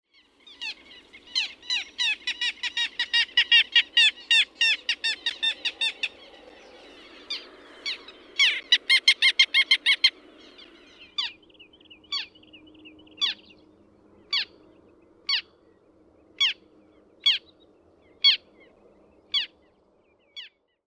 Pikkulokki
Hydrocoloeus minutus
Ääni: Nenäsointinen, hieman naakkamainen keäk-gneäk.